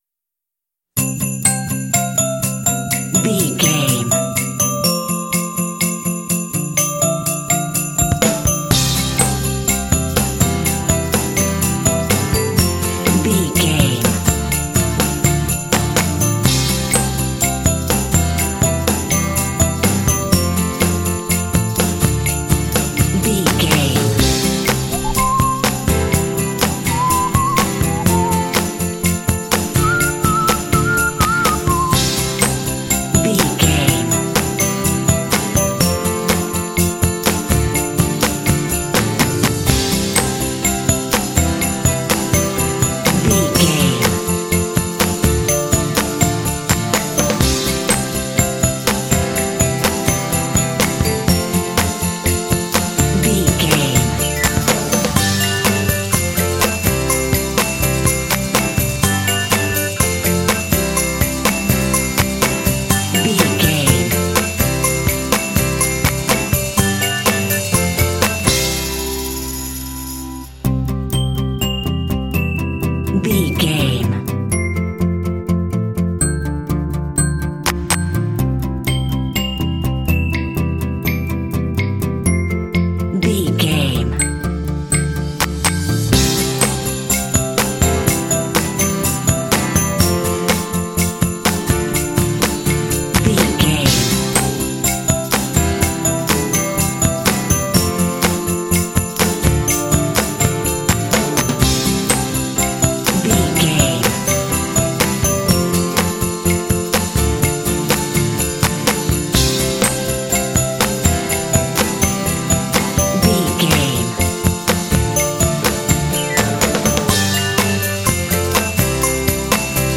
Ionian/Major
happy
positive
uplifting
joyful
bouncy
festive
drums
acoustic guitar
bass guitar
electric organ
contemporary underscore